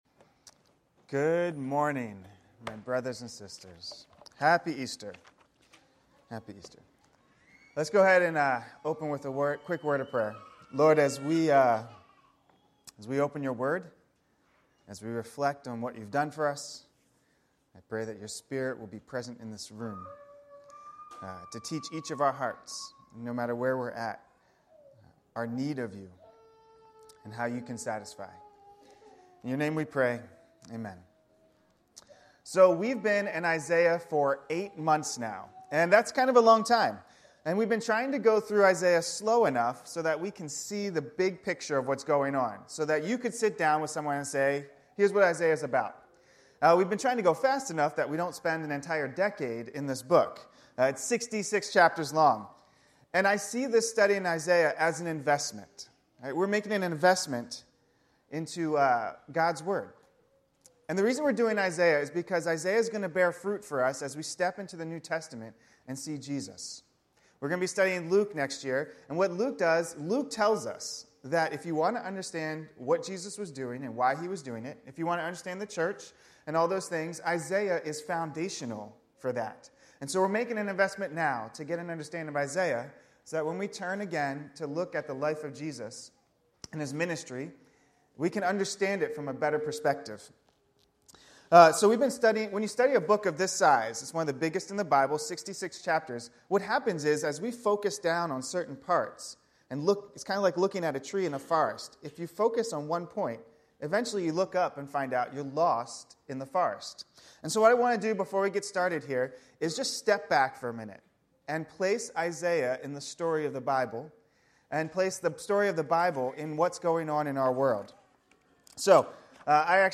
Sermons | Anchor Community Church